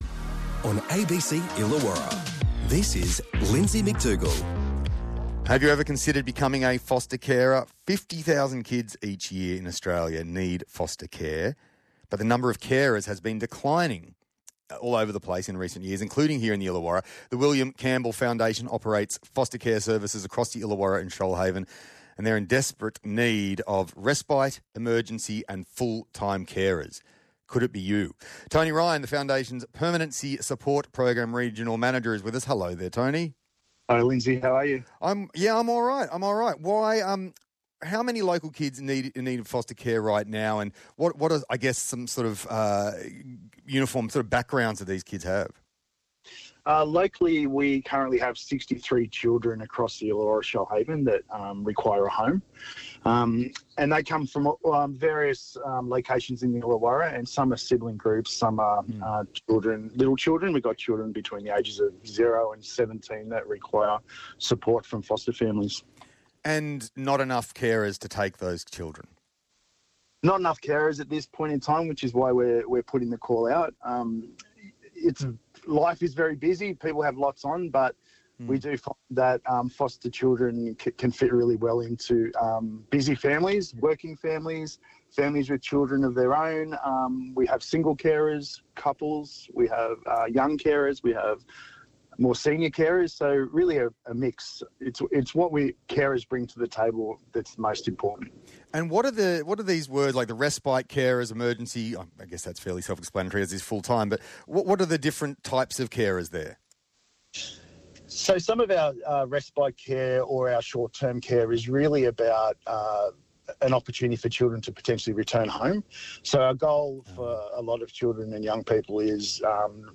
ABC Illawarra Interview